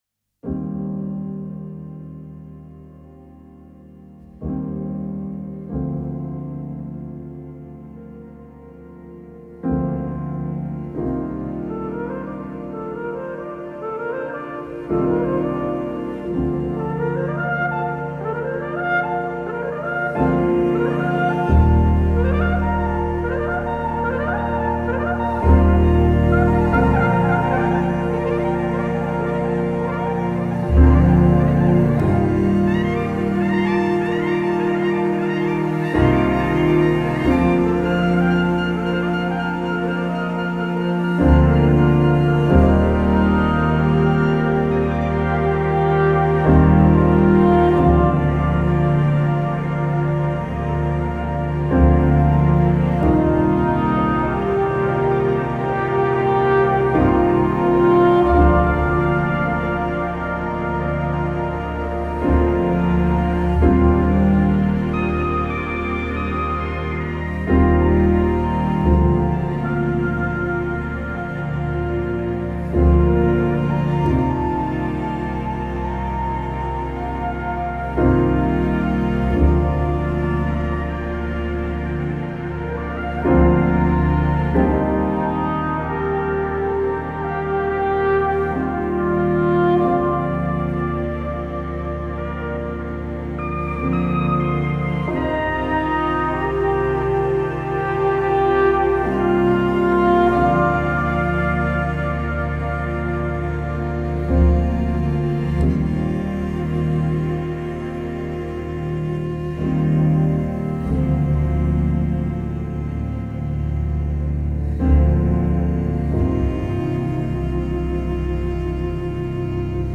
merveilles symphoniques à cordes